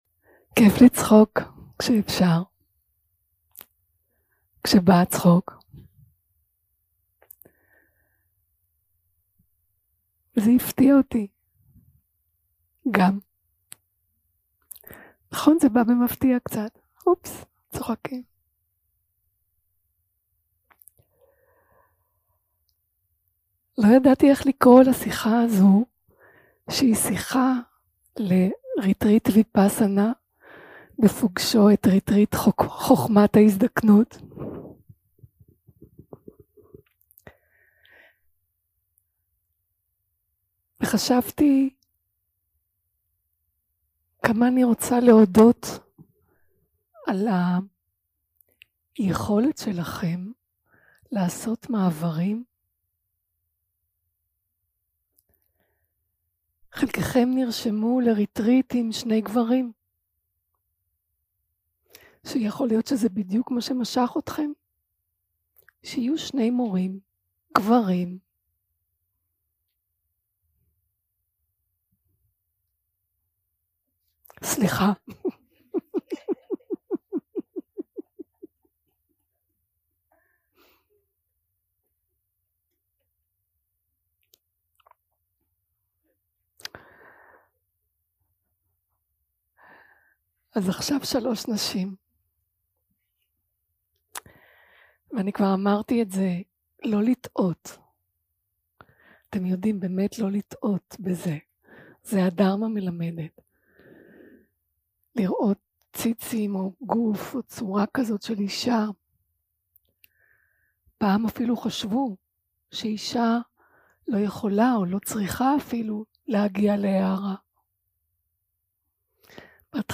יום 2 – הקלטה 5 – ערב – שיחת דהארמה - ויפאסנה פוגשת בחוכמת ההזדקנות בעת מלחמה
יום 2 – הקלטה 5 – ערב – שיחת דהארמה - ויפאסנה פוגשת בחוכמת ההזדקנות בעת מלחמה 0:00 0:00 סוג ההקלטה: Dharma type: Dharma Talks שפת ההקלטה: Dharma talk language: Hebrew